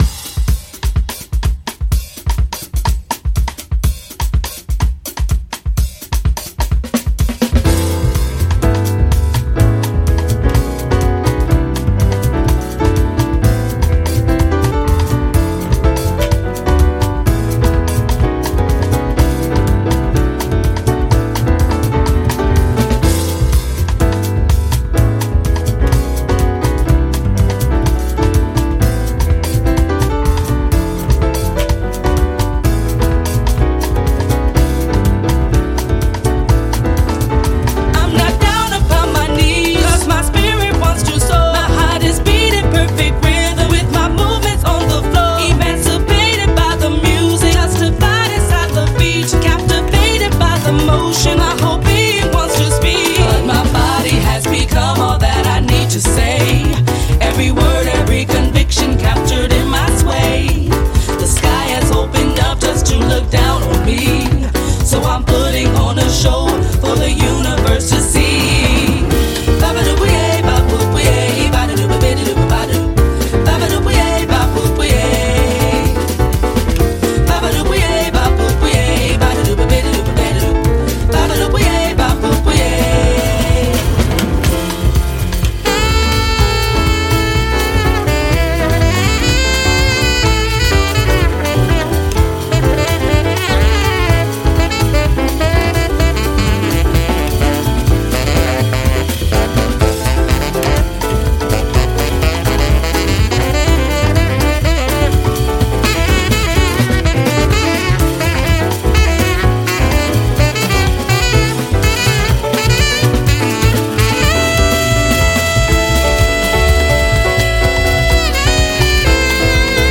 ジャンル(スタイル) DEEP HOUSE / JAZZ HOUSE / DISCO / SOULFUL HOUSE